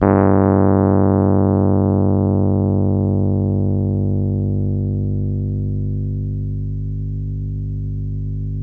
RHODES CL01L.wav